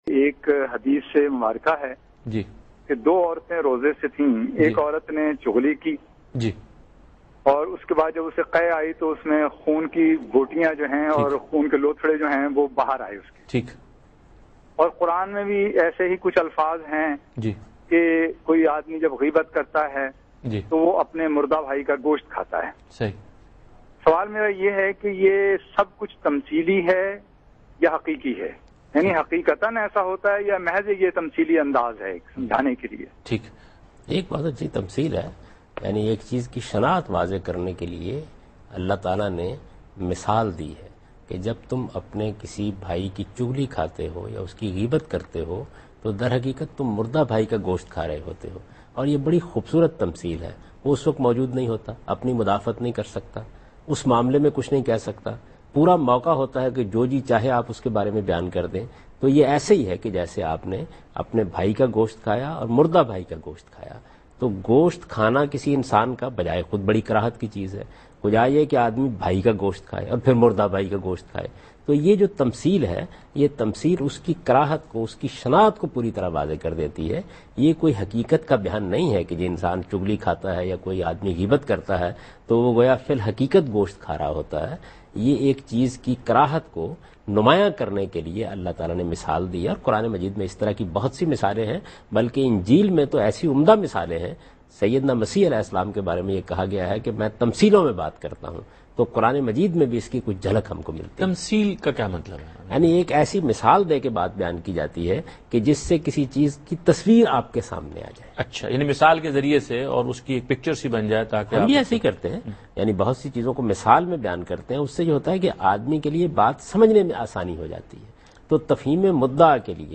Category: TV Programs / Dunya News / Deen-o-Daanish /
Javed Ahmad Ghamidi Answers a question regarding "Backbiting and Sowing Seeds of Dissension" in program Deen o Daanish on Dunya News.